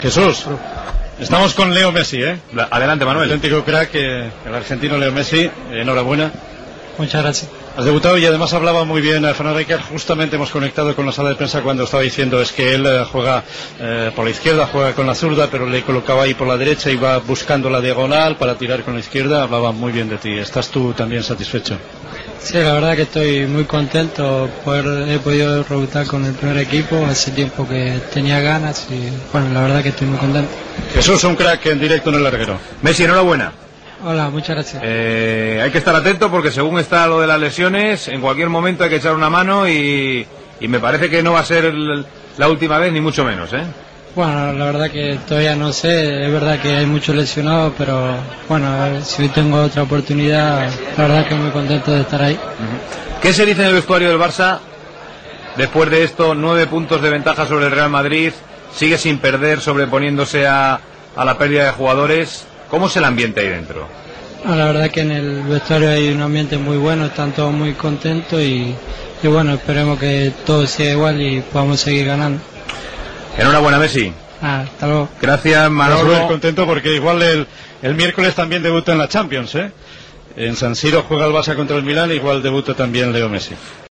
Entrevista informativa al jugador del Futbol Club Barcelona Leo Messi després del partit del seu debut amb el primer equip. Feta a l'Estadi Olímpic de Montjuic després del partit R.C.D.Espanyol - Futbol Club Barcelona (resultat final 0 a 1)
Esportiu